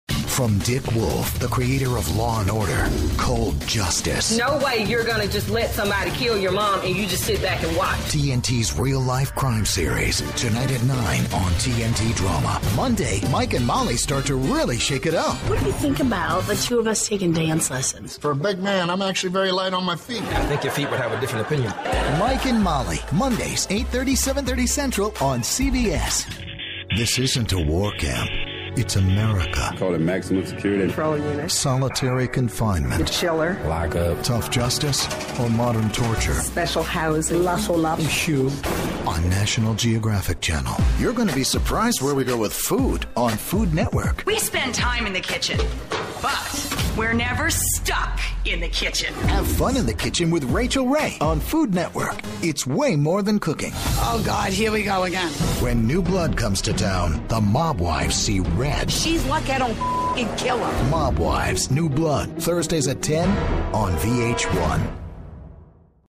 compelling, dynamic, velvety, contemplative, friendly, sexy, romantic, buttery, adaptable and easy to work with
englisch (us)
Sprechprobe: Sonstiges (Muttersprache):